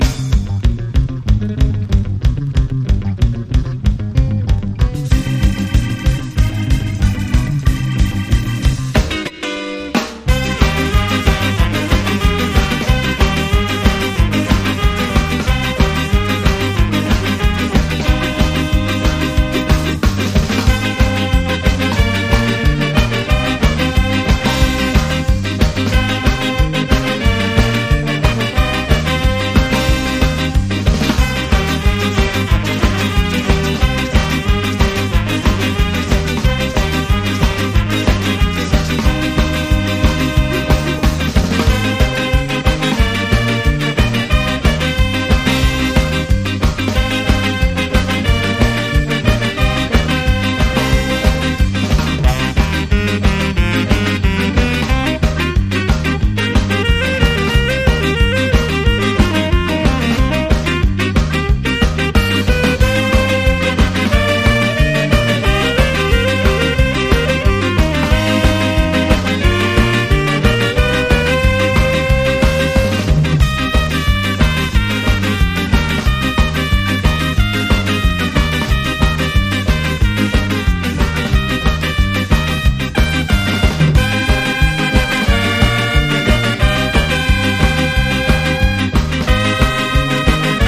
2 TONEスカ・スタイル日本代表！